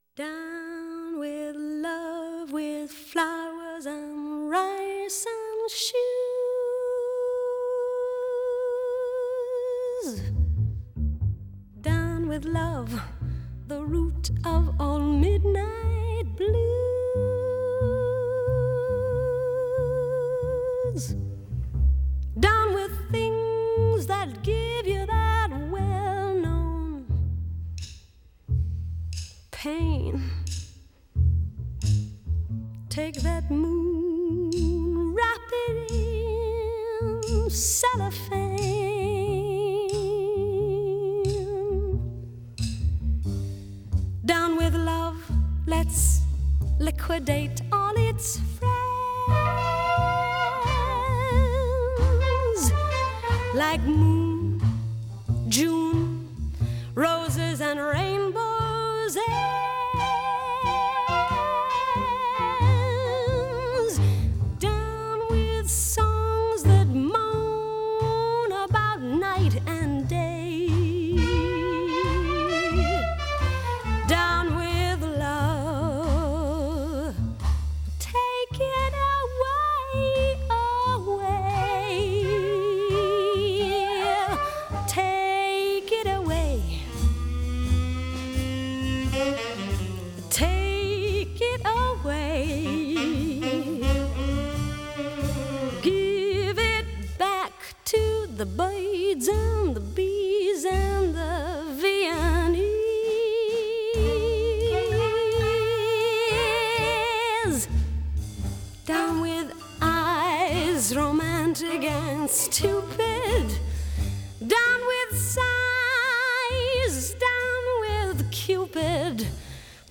1937   Genre: Musical   Artist